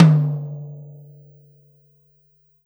Tom Shard 09.wav